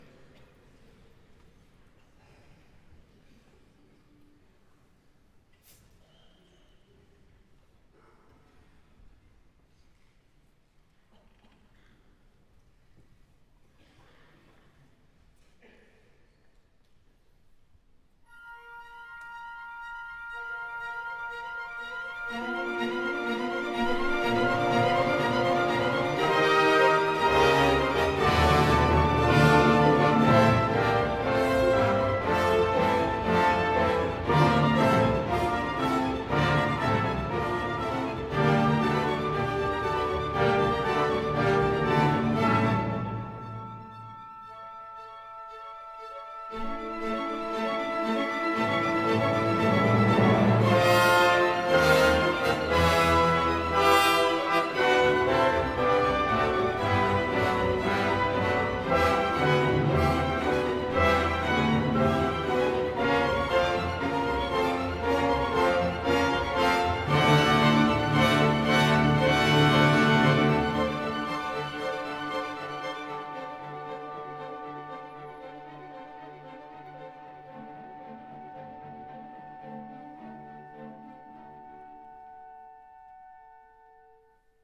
(notes d'écoute) Anton Bruckner par Daniel Barenboim
On peut voir ici précisément la Symphonie n°3 dirigée par Daniel Barenboim, à la tête de la Staatskapelle, concert donné à la Philharmonie de Paris, le 7 janvier 2017 (vidéo en principe disponible 5 mois, durée un peu plus d’une heure)